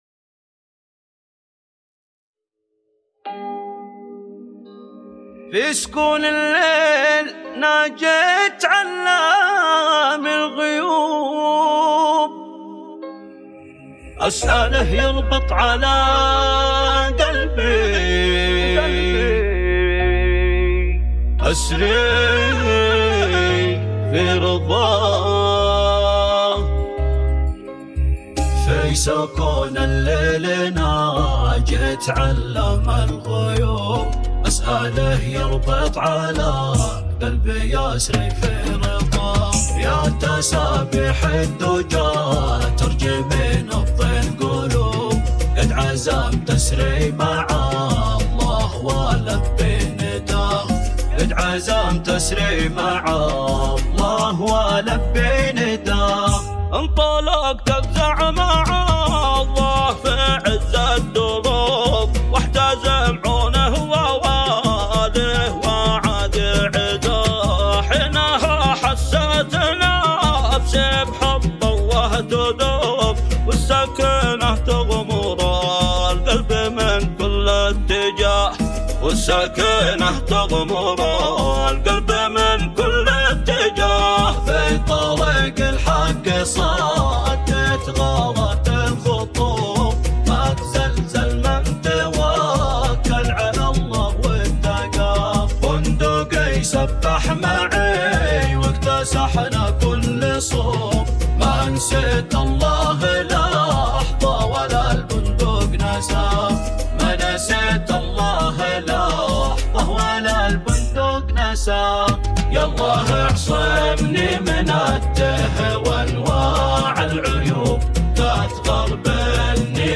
زامل
كورال